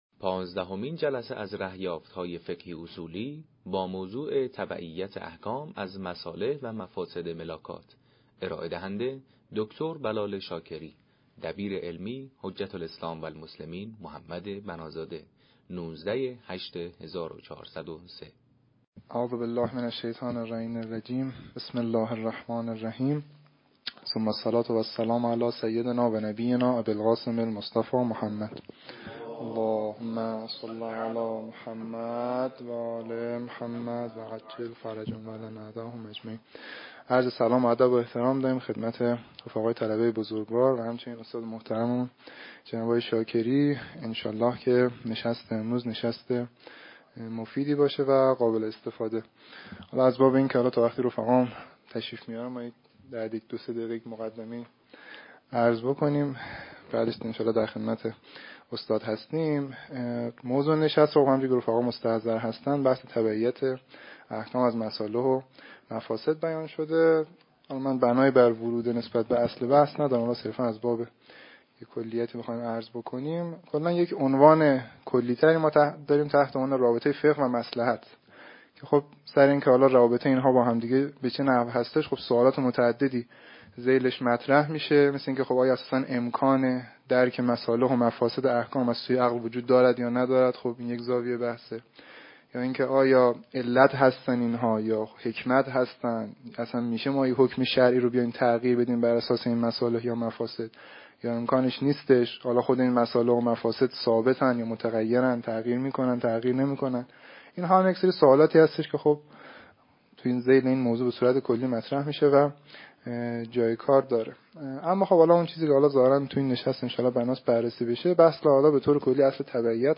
نشست علمی تبعیت احکام از مصالح و مفاسد «ملاکات» | مدرسه علمیه عالی نواب